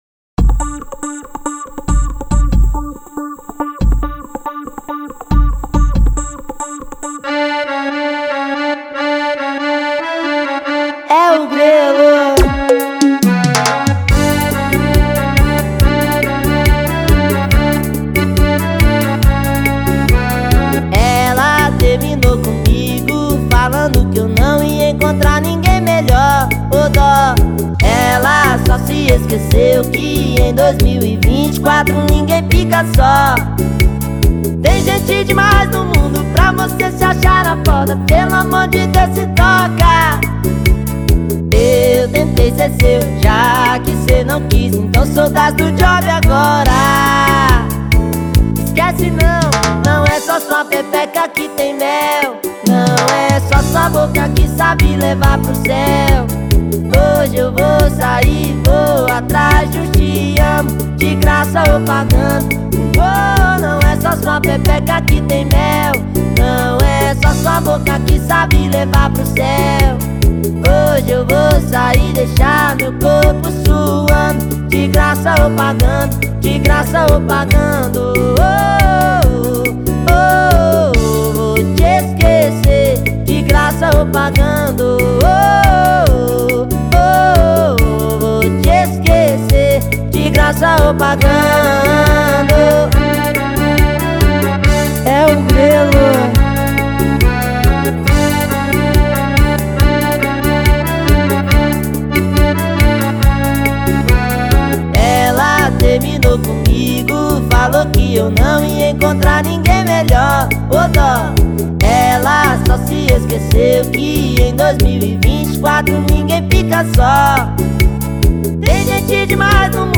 Arrocha